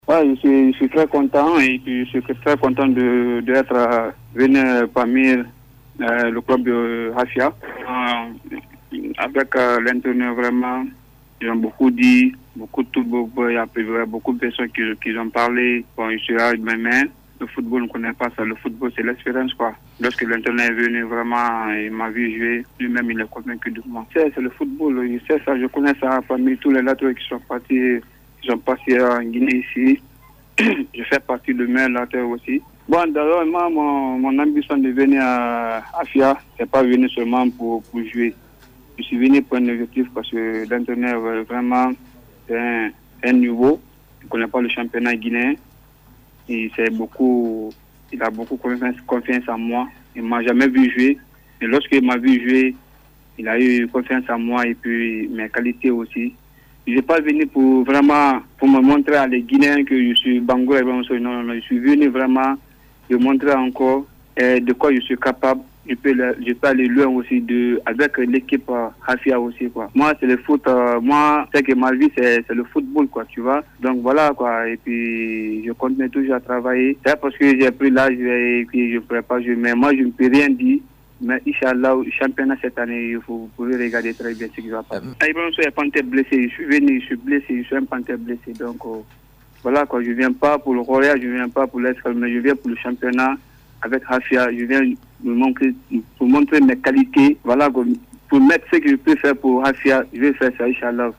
Il l’a dit dans l’émission Cis Live de nos confrères de Cis Radio.